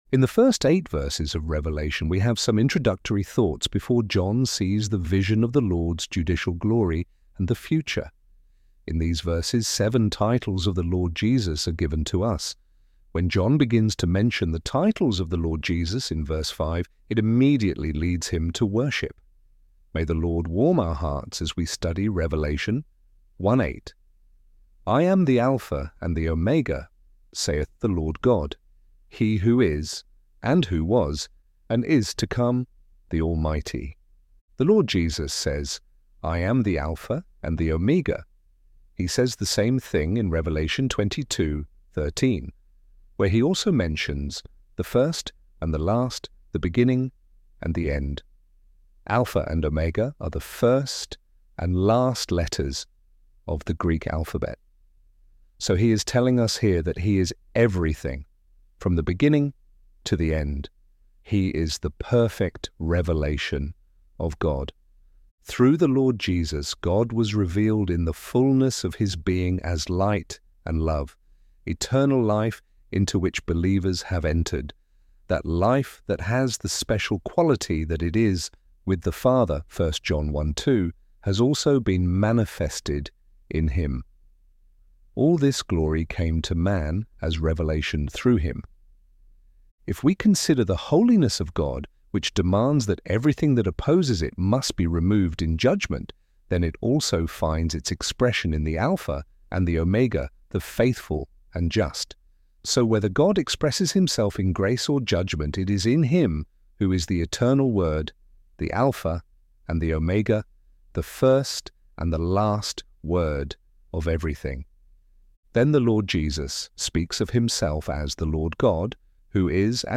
Bible Study
ElevenLabs_The_Alpha_and_the_Omega.mp3